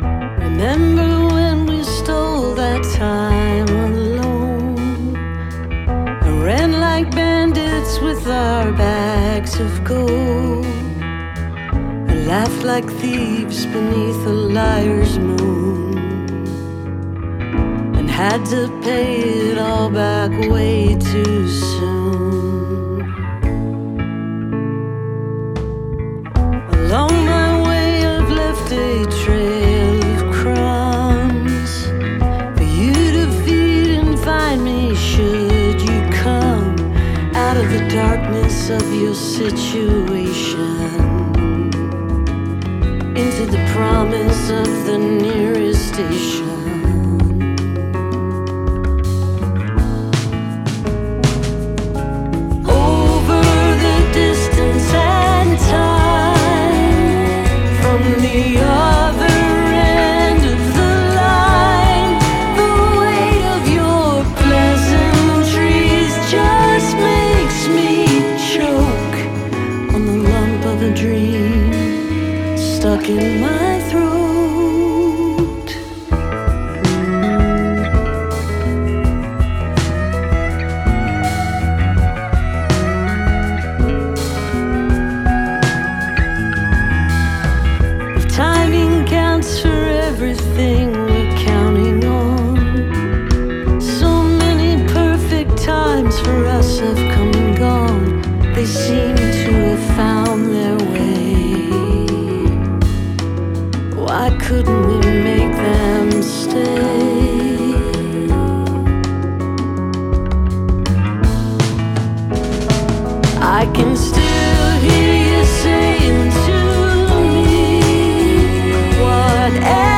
(captured from webcast)
album version